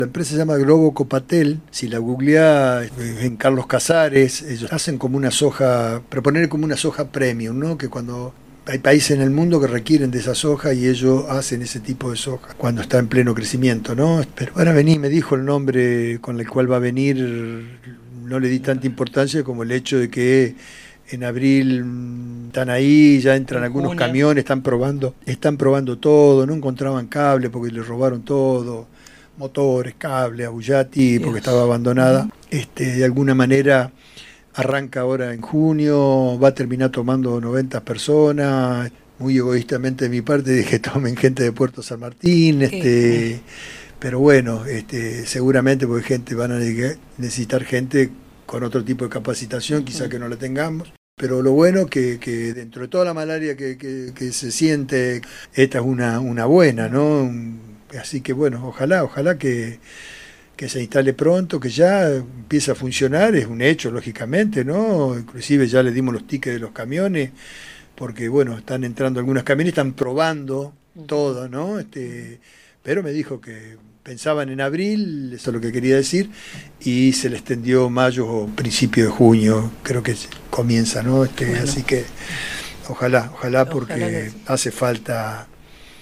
El mandatario habló al respecto durante una entrevista en el programa Con Voz de FM 102.9 Radio Nueva Estrella, donde brindó detalles sobre el proceso de reapertura del establecimiento.